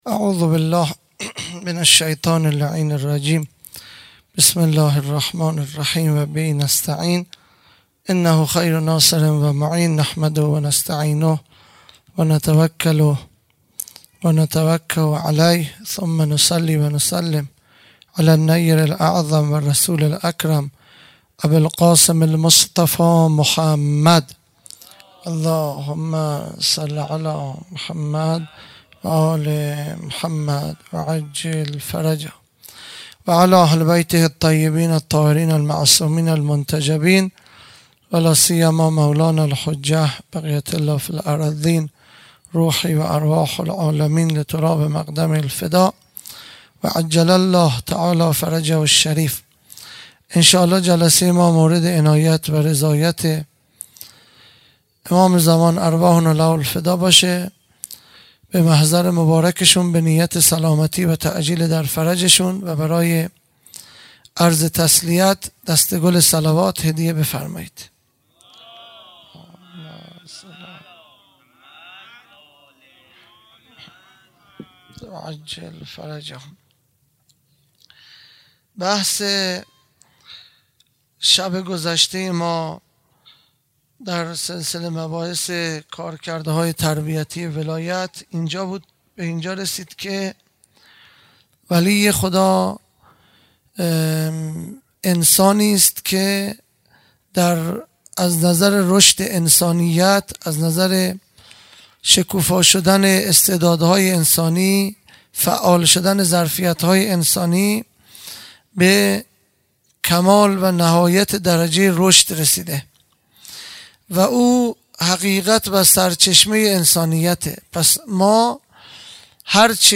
خیمه گاه - هیئت بچه های فاطمه (س) - سخنرانی | أین السبب المتصل بین الارض و السماء | دوشنبه ۲۵ مرداد ۱۴۰۰